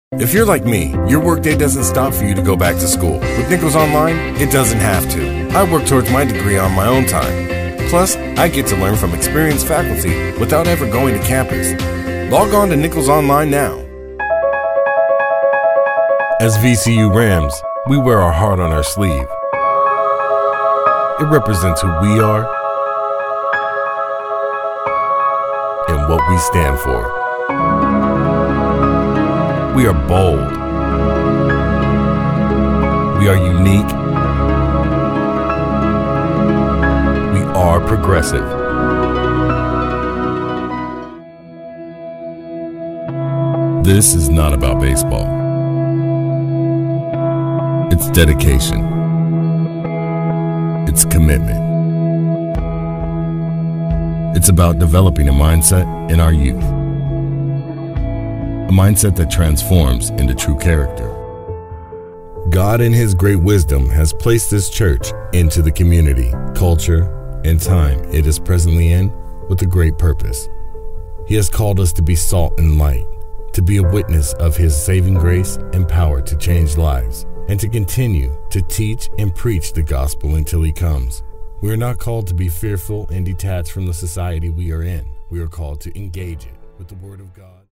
Deep rich voice with calm and energetic tones.
Sprechprobe: Werbung (Muttersprache):